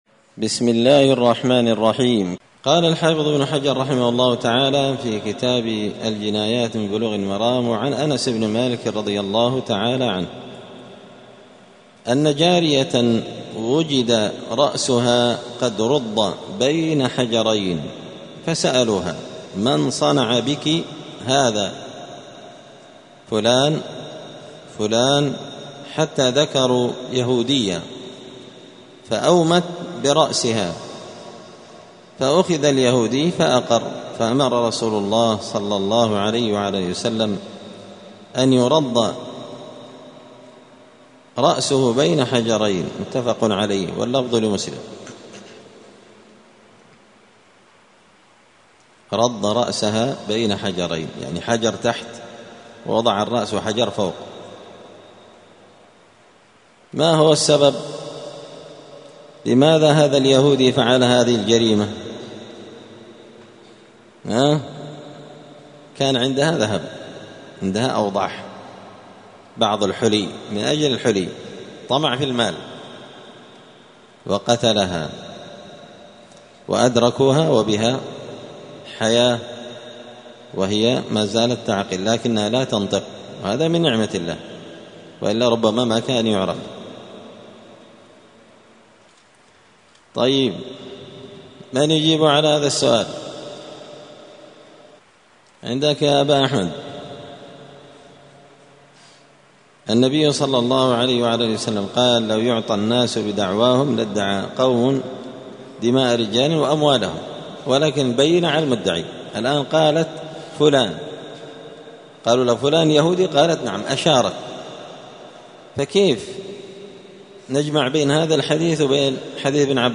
*الدرس السابع (7) {باب قتل الرجل بالمرأة}*
دار الحديث السلفية بمسجد الفرقان بقشن المهرة اليمن